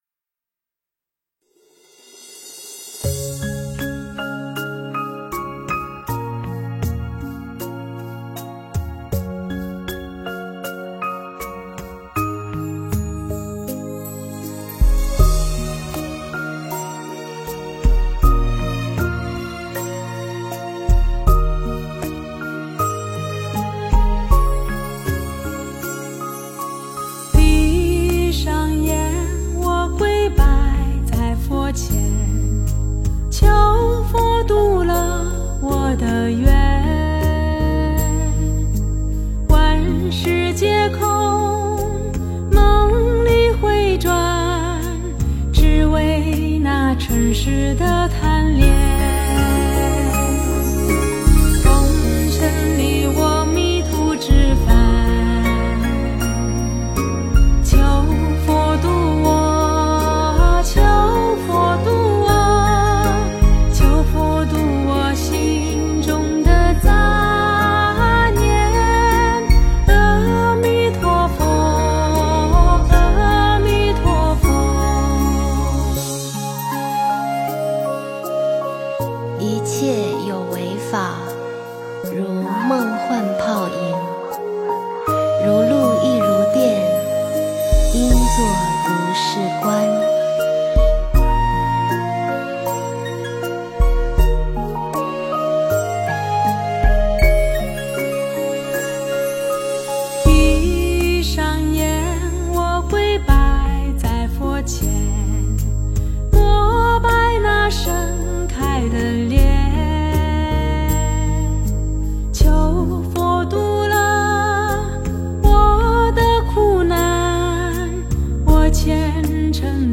佛音 凡歌 佛教音乐 返回列表 上一篇： 我佛慈悲